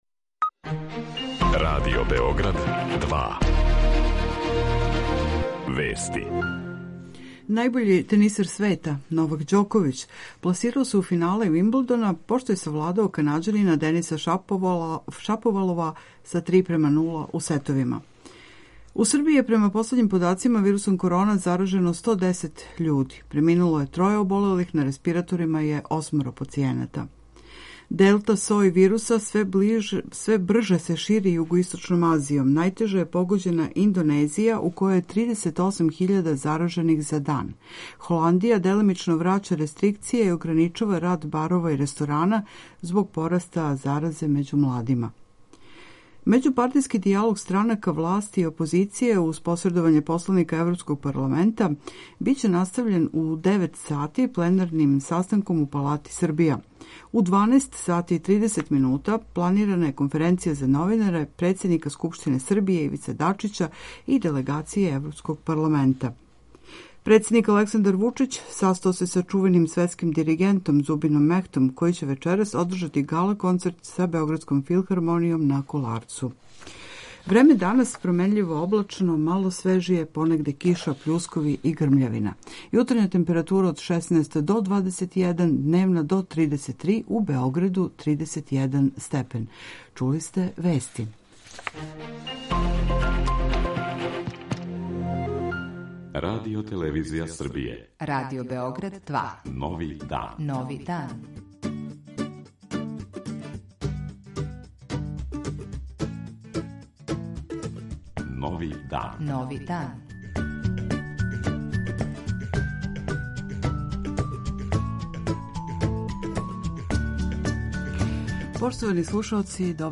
Разговарамо са...